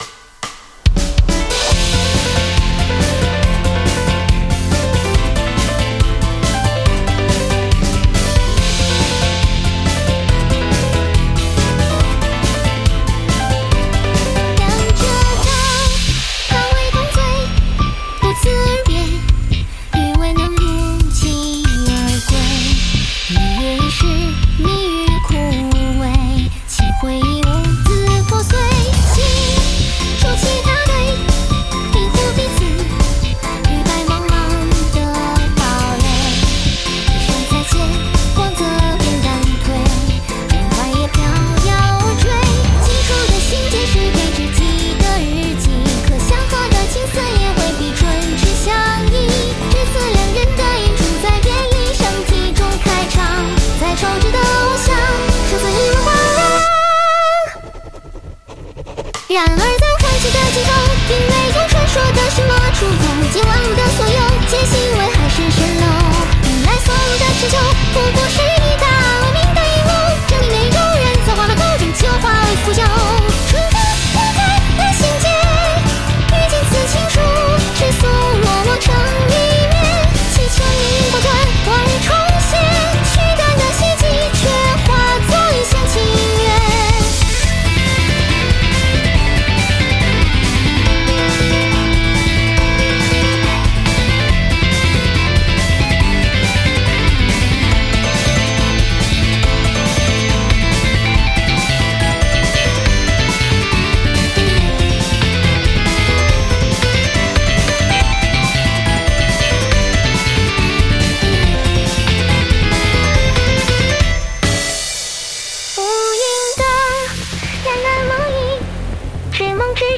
空拍的留白相当娴熟，用镲重置节拍的节奏感也恰到好处。